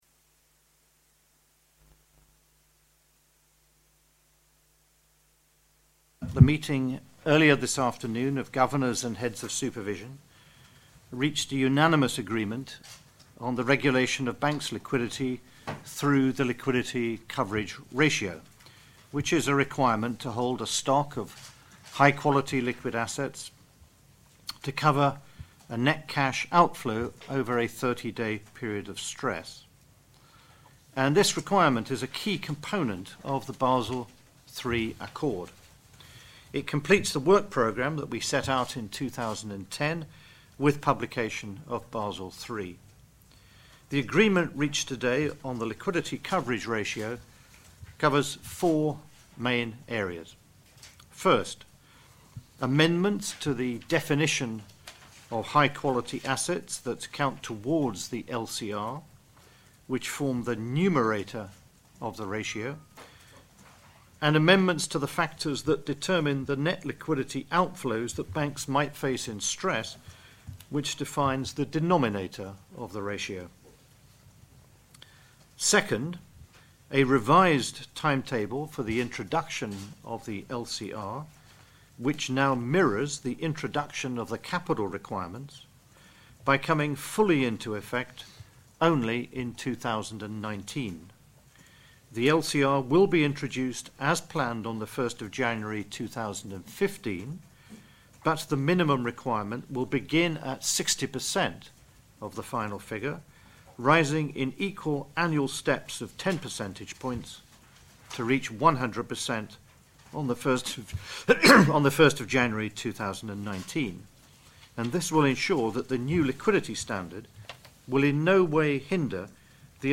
Audio file of the introductory remarks from GHOS Chairman Mervyn King and the Basel Committee on Banking Supervision's Chairman Stefan Ingves as well as the question and answer session which followed.